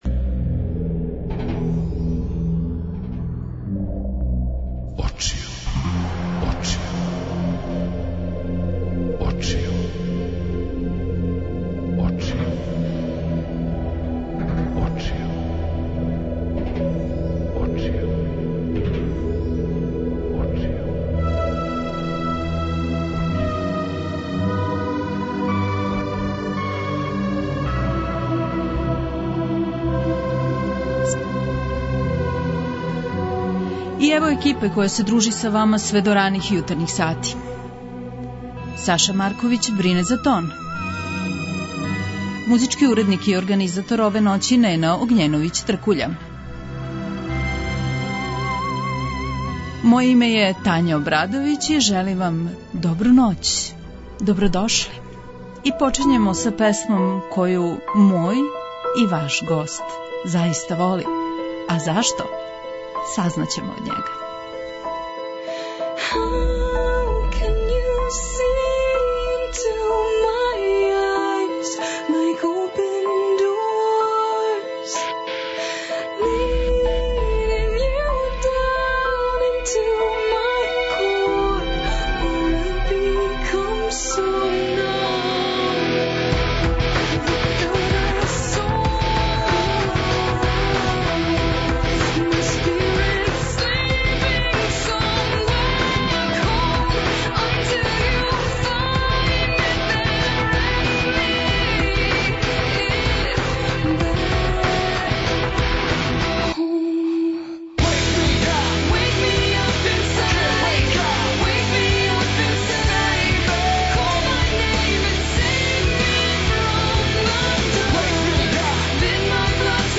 Гост емисије: Дадо Топић, музичар